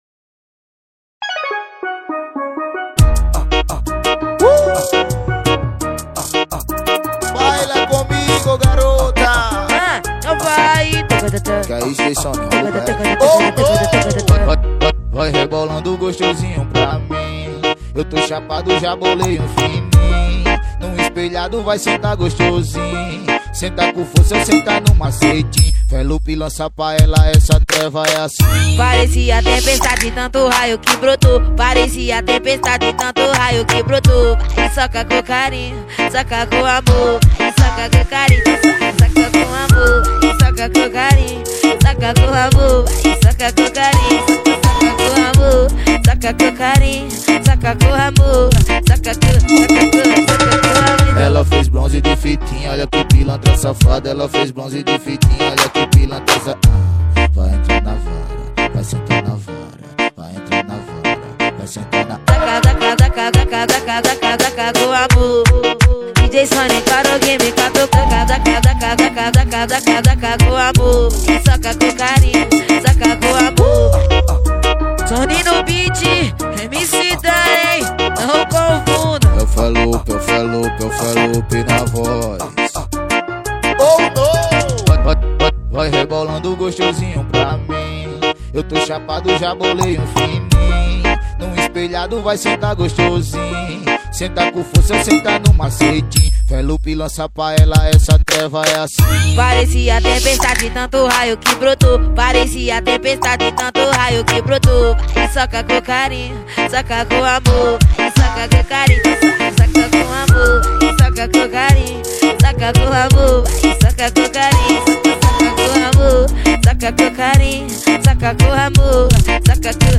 2024-02-14 19:56:40 Gênero: Funk Views